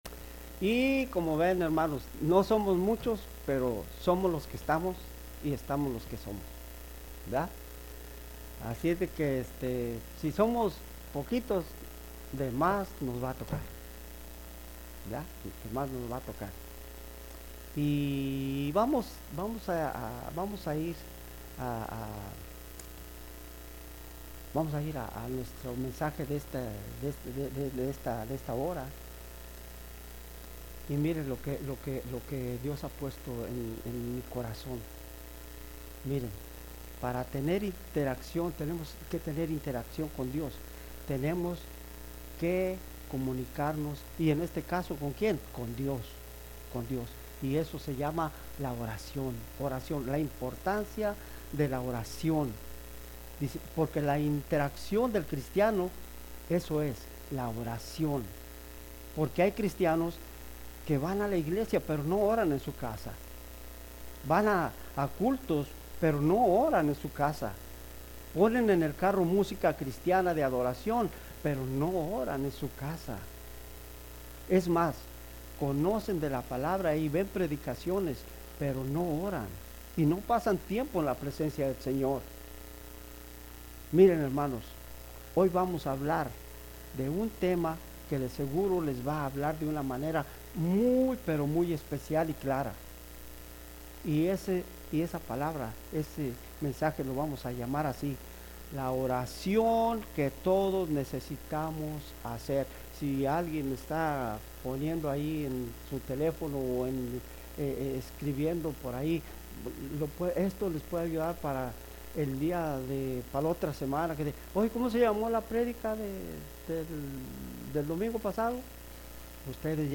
Sermons | Mercy Springs Church of the Nazarene
Acabo de salir de un servicio dominical realmente conmovedor y siento una profunda satisfacción.